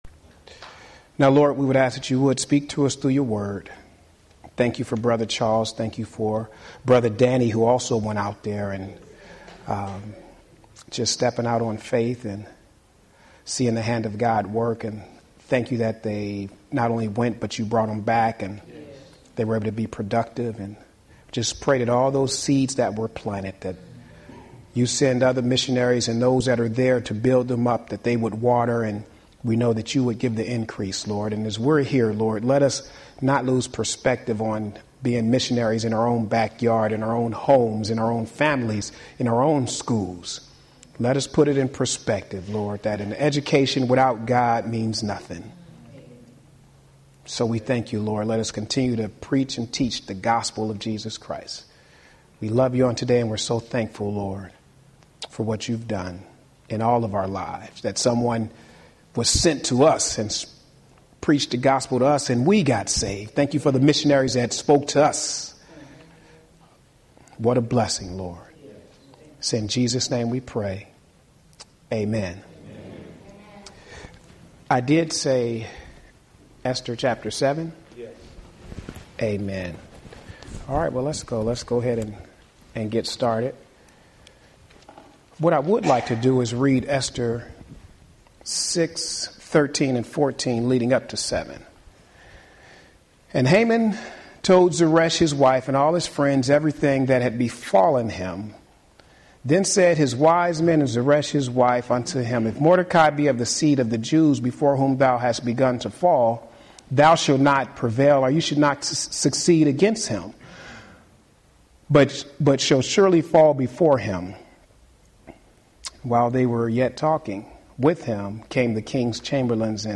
Home › Sermons › Spirit Filled and Spirit Sealed – Esther, Mordecai, Haman